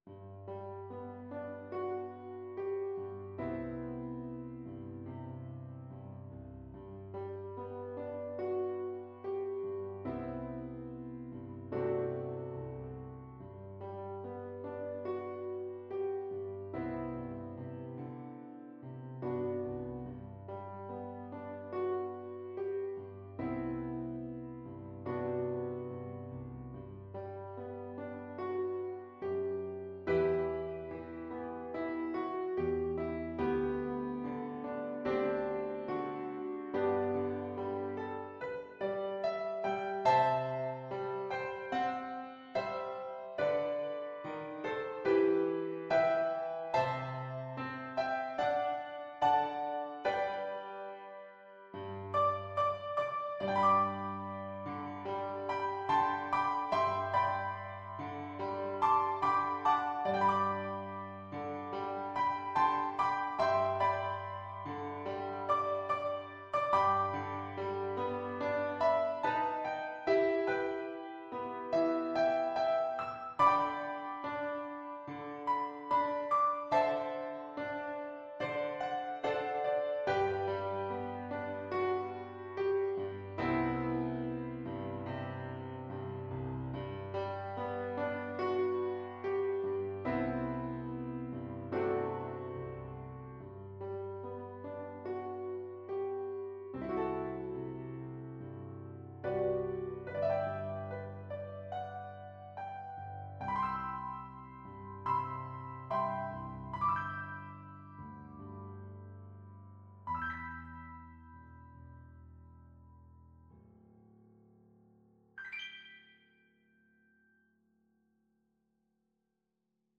4/4 (View more 4/4 Music)
Molto espressivo =c.72